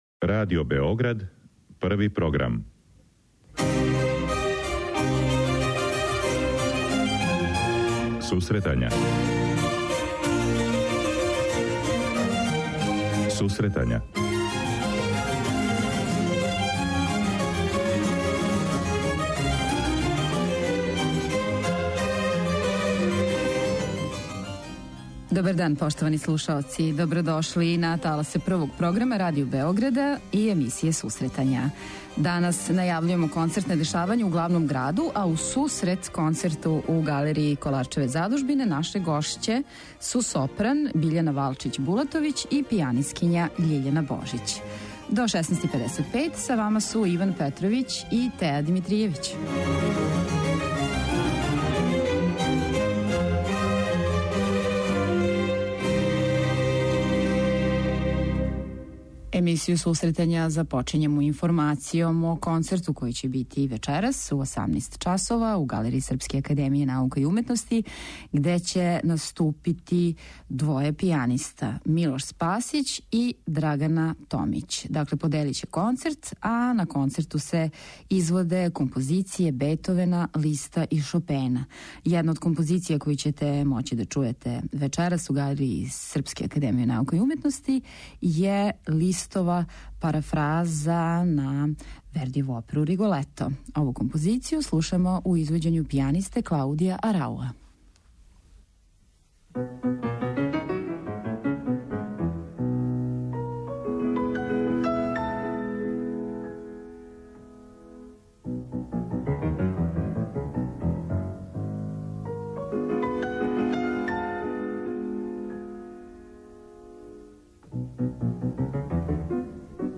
преузми : 26.33 MB Сусретања Autor: Музичка редакција Емисија за оне који воле уметничку музику.